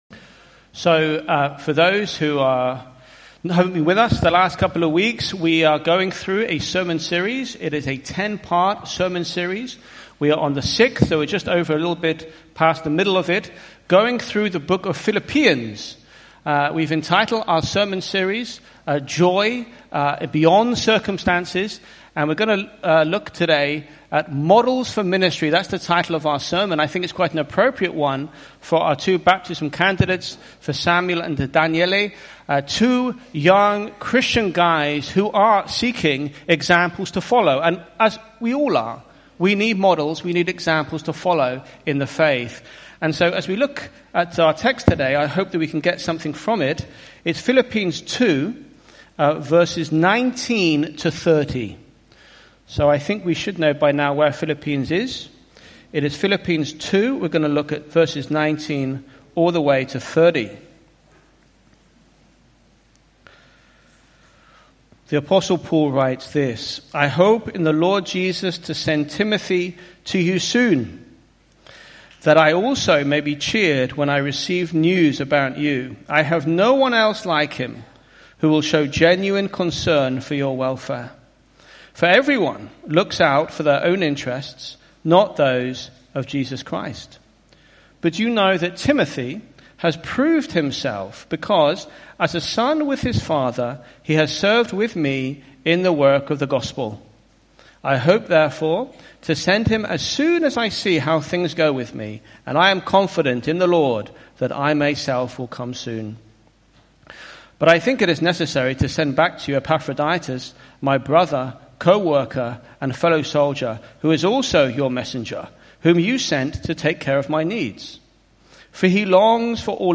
IBC Hamburg Sermon